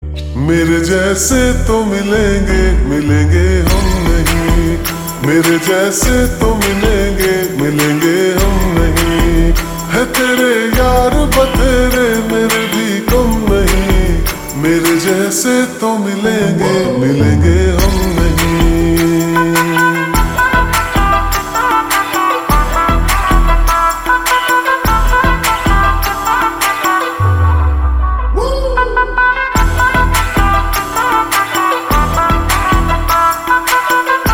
Hindi Songs
( Slowed + Reverb)